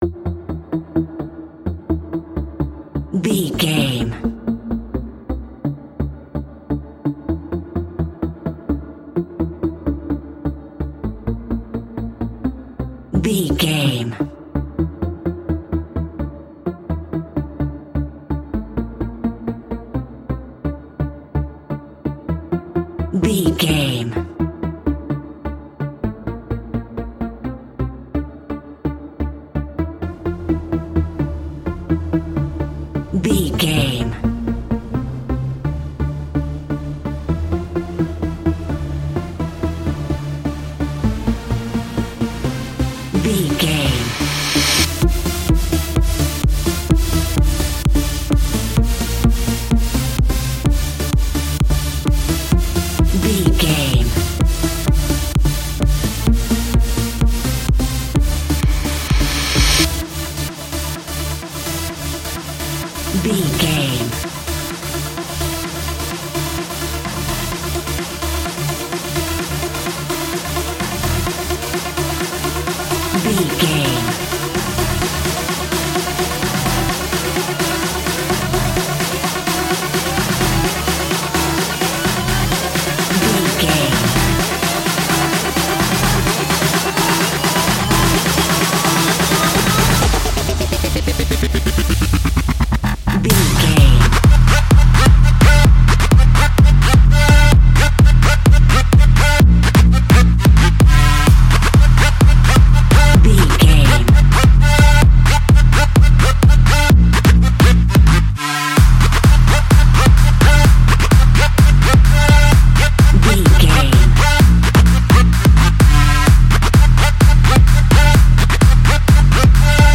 Drone Darkstep Trance.
Epic / Action
Fast paced
Aeolian/Minor
aggressive
groovy
futuristic
driving
energetic
drum machine
synthesiser
trance
acid house
electronic
synth leads
synth bass